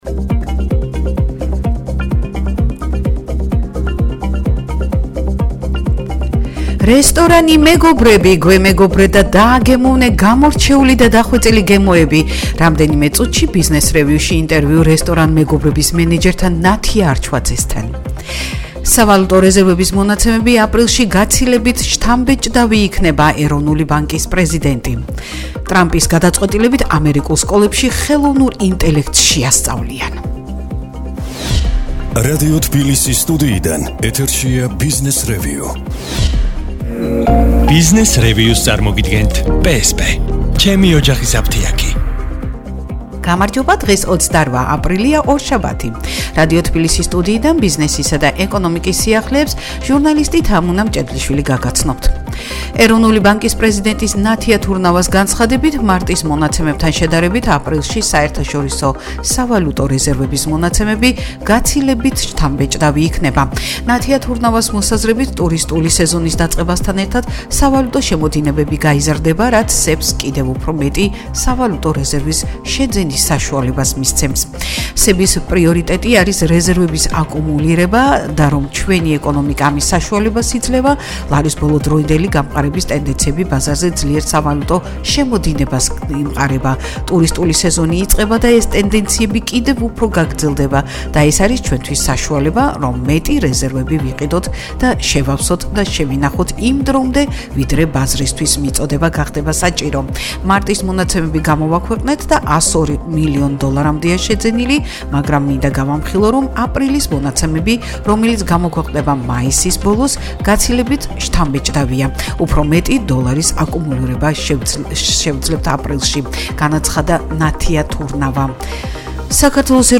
რესტორანი „მეგობრები“ - გვემეგობრე და დააგემოვნე გამორჩეული და დახვეწილი გემოები - ინტერვიუ რესტორან